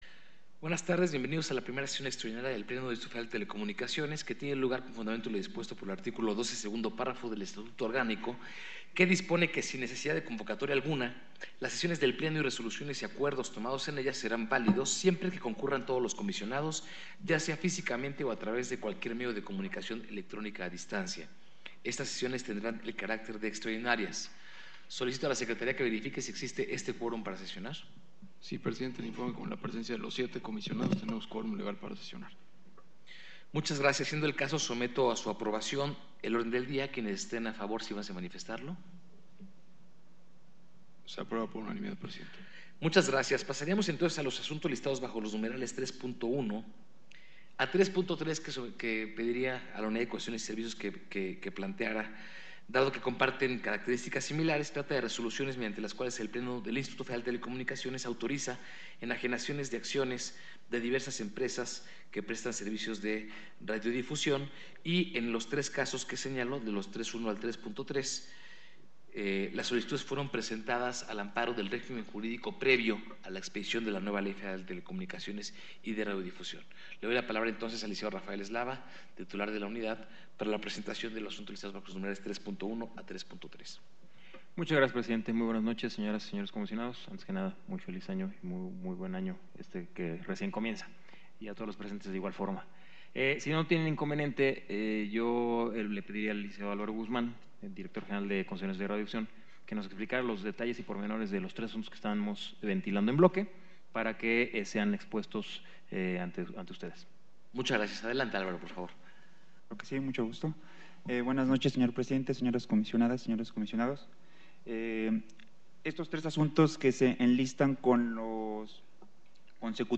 I Sesión Extraordinaria del Pleno 6 de enero de 2015